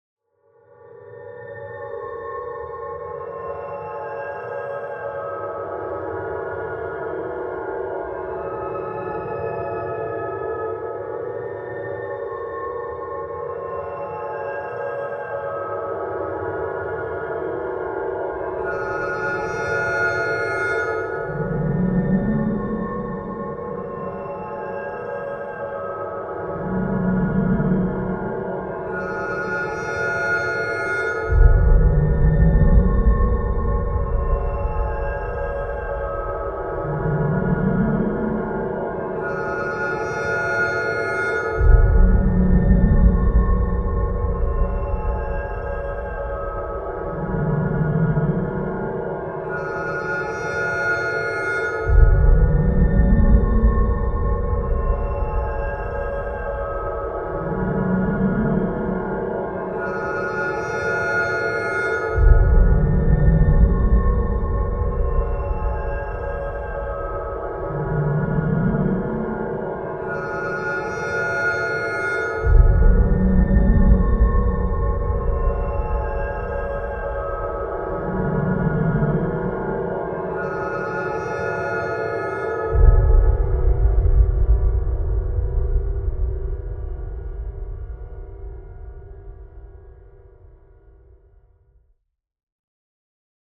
Huge mechanisms lurch through dark mists.